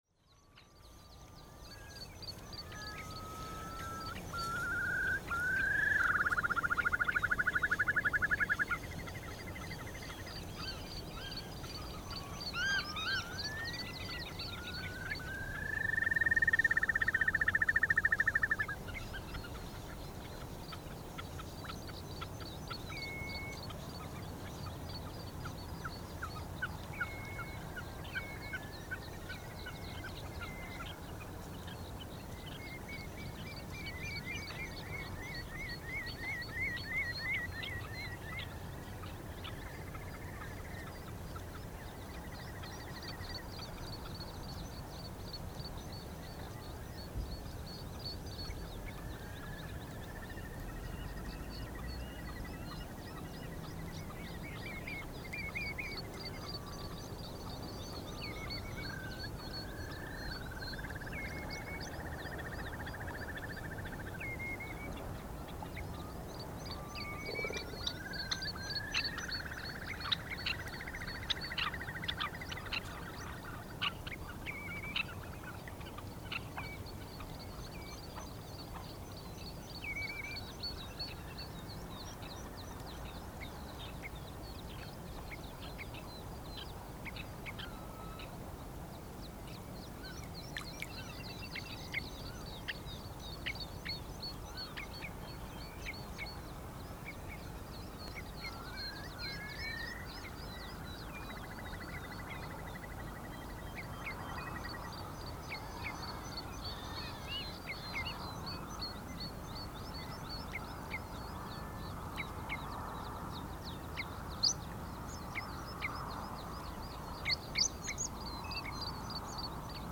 Posted in Náttúra, tagged Friðland í Flóa, Fuglar, Fuglasöngur, Korg MR1000, Nature reserve, Rode NT1a, Sound devices 552 on 1.11.2011| 10 Comments »
Vindstyrkur var frá því að vera logn allt að 8 m/s sem því miður má stundum heyra í þessari löngu upptöku.
Þó enn heyrist suð í tækjum (hvítt suð) þá skiluðu þau ágætum upptökum af ótrúlega lágværum hljóðum sem vart voru merkjanleg með berum eyrum.
Þá er suðið í briminu út með suðurströndinni mjög greinilegt.
Í upptökunni má heyra í mörgum fuglum.
The recording below was recorded between 1:00 to 2:00 am. The recording device give a brilliant result in this quiet atmosphere.